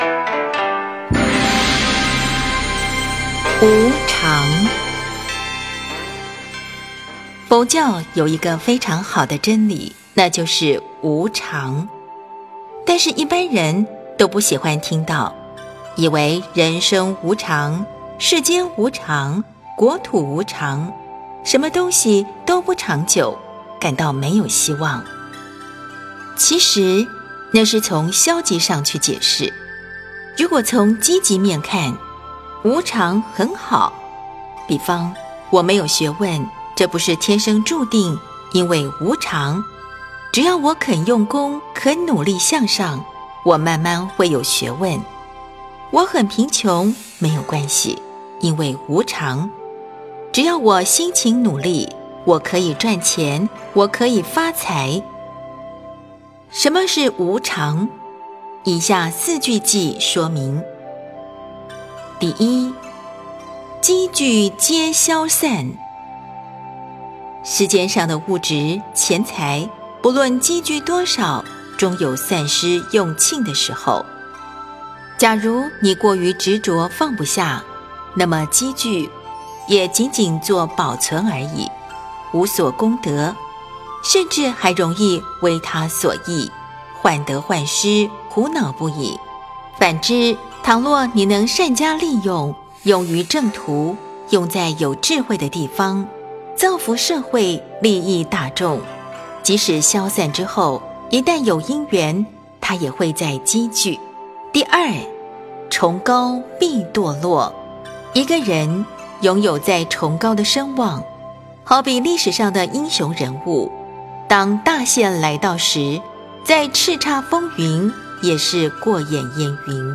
佛音 冥想 佛教音乐 返回列表 上一篇： 05.